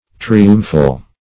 Triumphal \Tri*um"phal\, n.